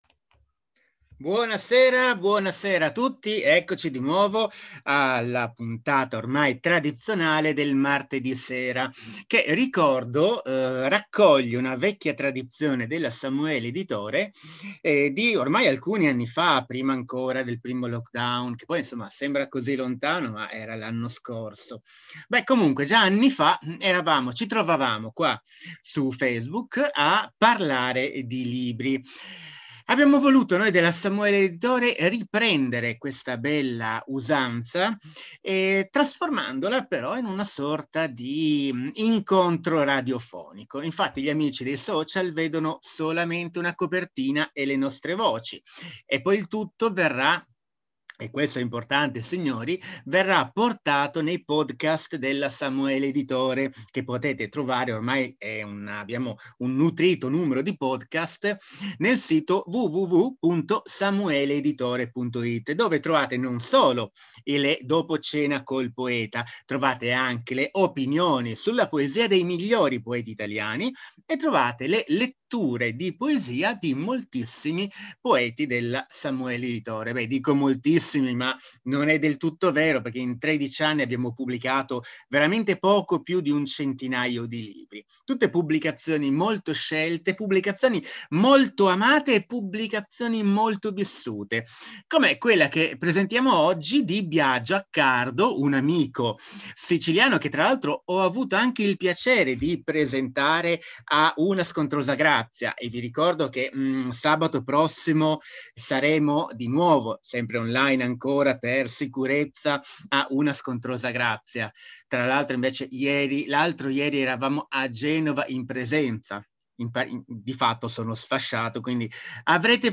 Piccole dirette in solo streaming audio coi poeti della Samuele Editore